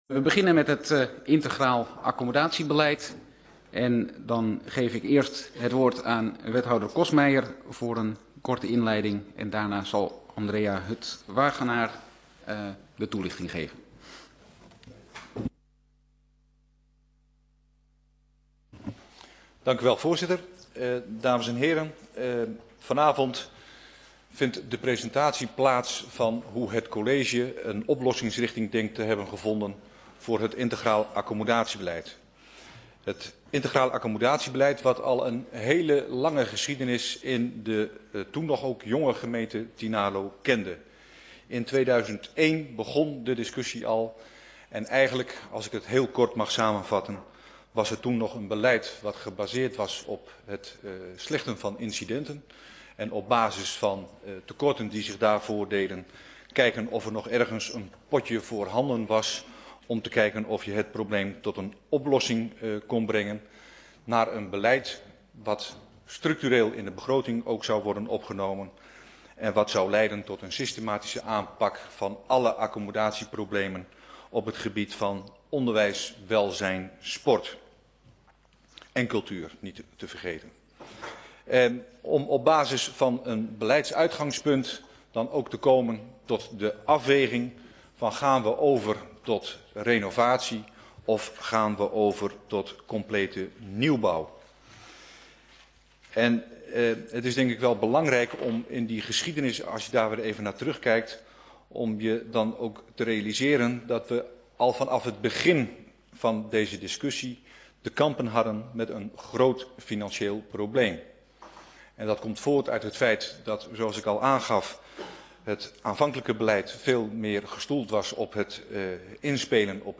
Informatiebijeenkomst 20 december 2011 19:30:00, Gemeente Tynaarlo
Download de volledige audio van deze vergadering